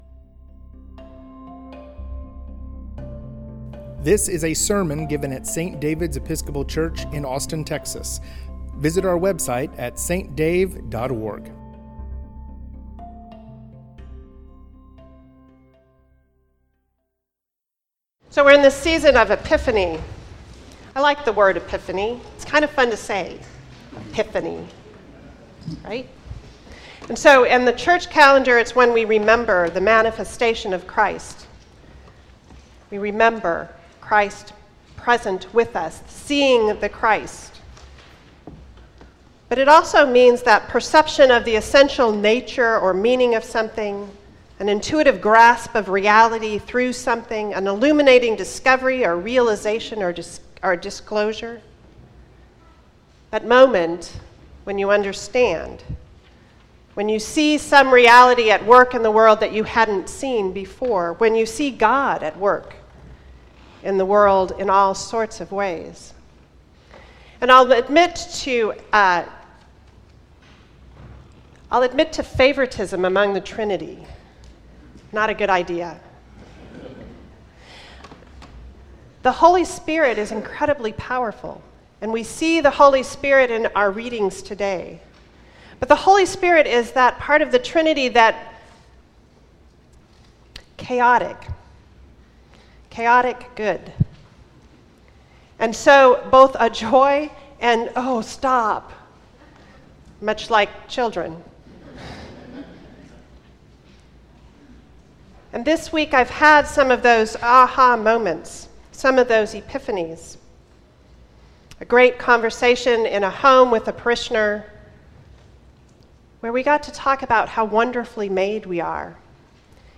Sermon
st-david-s-episcopal-church-71748-o.mp3